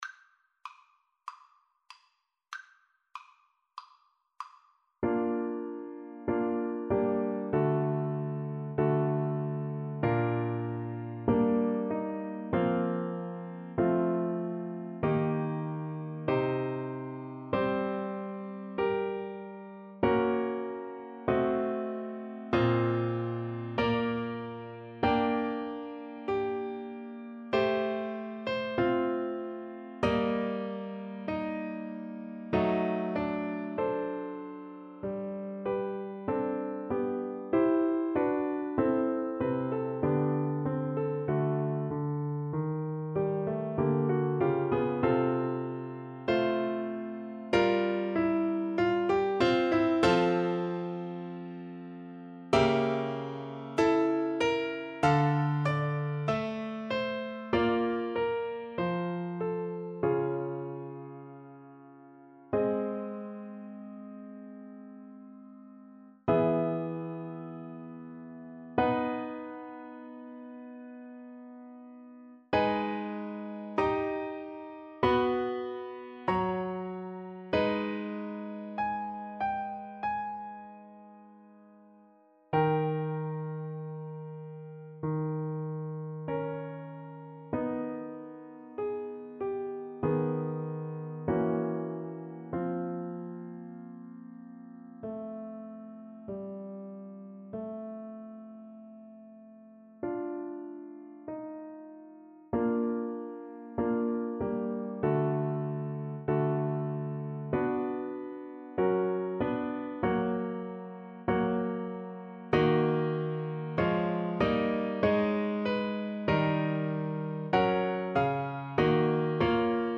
~ = 96 Alla breve. Weihevoll.
Classical (View more Classical French Horn Music)